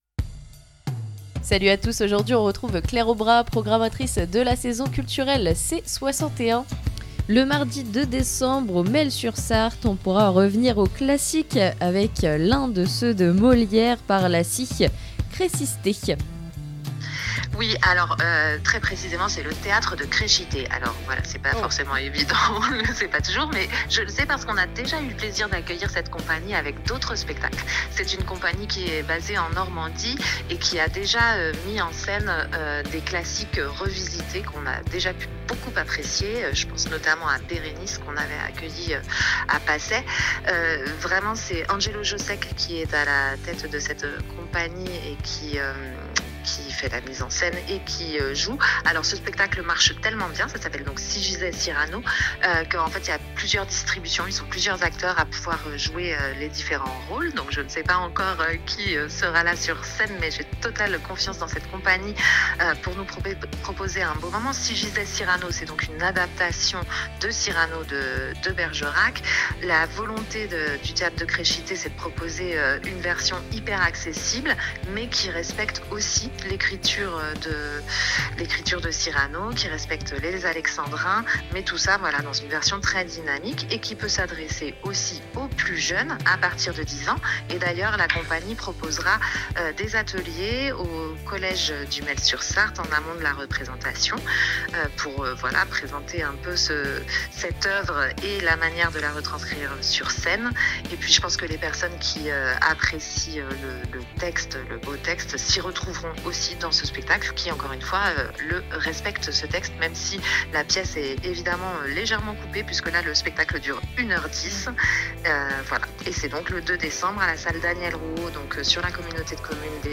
Interviews RCDF